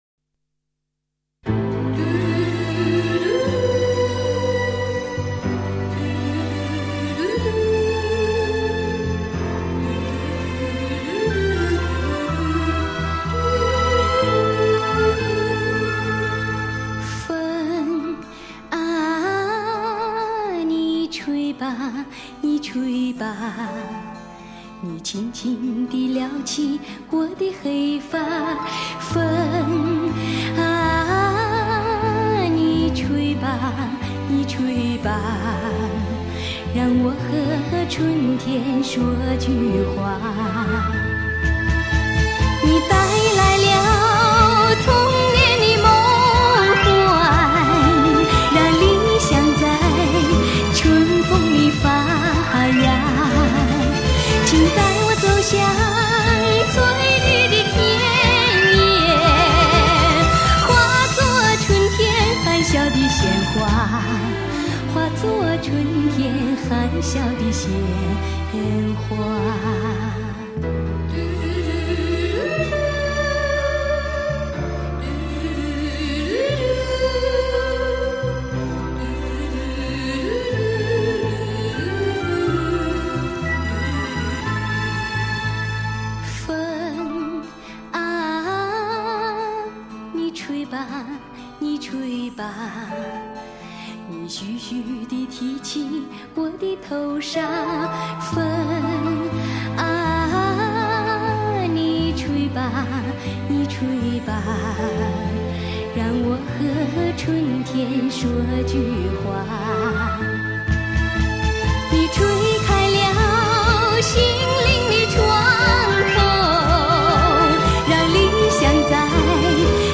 其声音清新甜美。